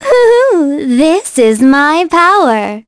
Cassandra-Vox_Victory.wav